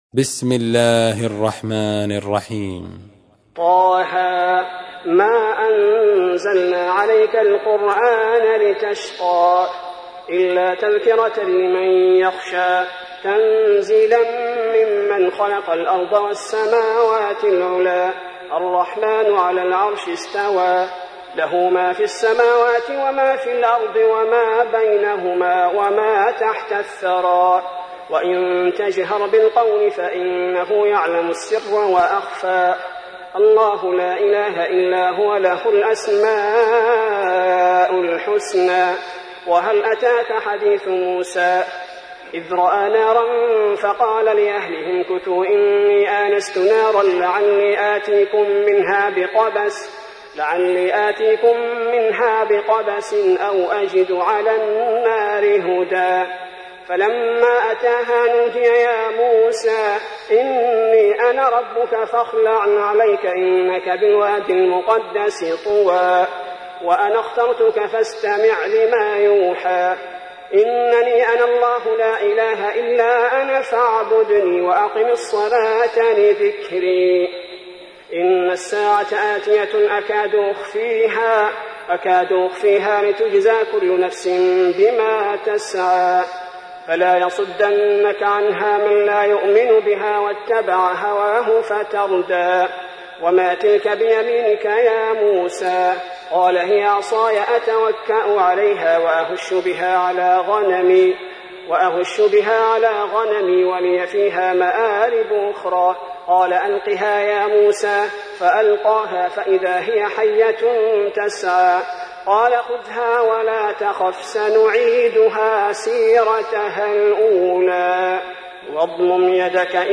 تحميل : 20. سورة طه / القارئ عبد البارئ الثبيتي / القرآن الكريم / موقع يا حسين